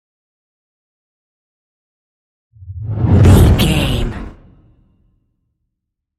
Whoosh deep
Sound Effects
Atonal
dark
intense
whoosh
sci fi